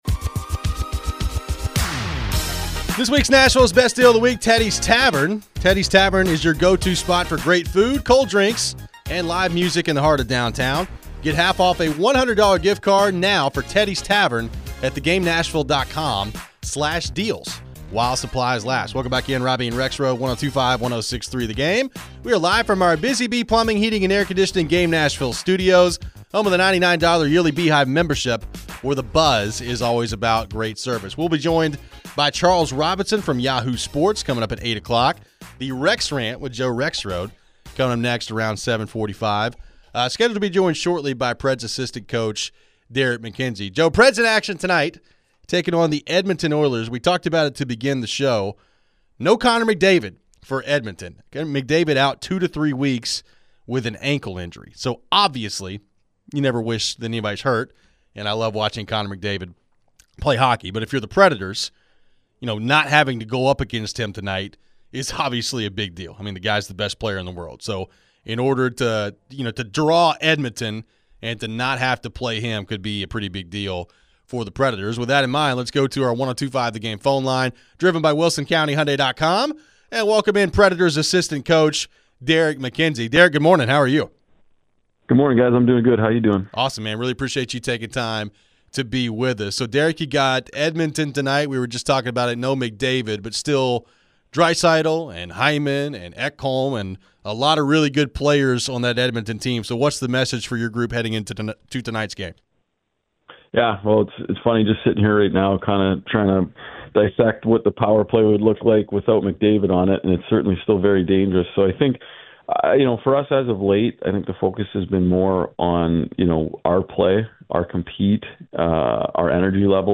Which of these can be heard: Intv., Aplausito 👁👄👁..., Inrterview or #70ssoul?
Inrterview